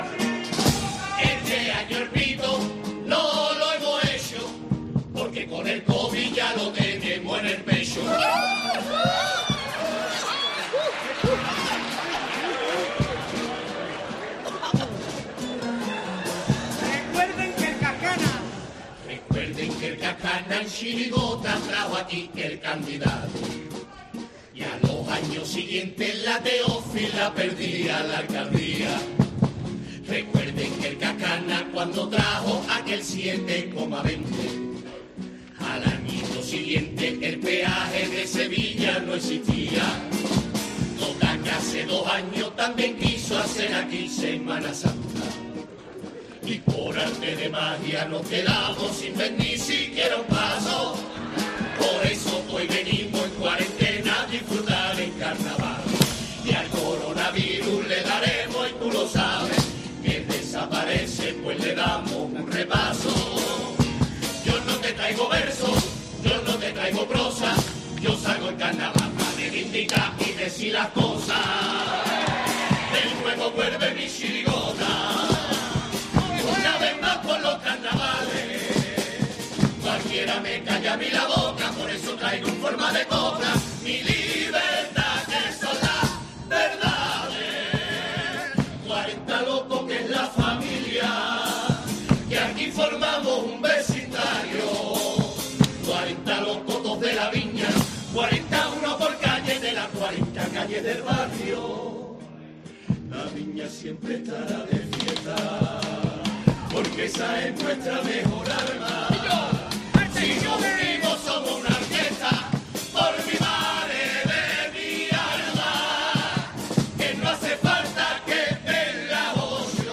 Carnaval de Cádiz
Primer pasodoble de la comparsa El club de los ignorantes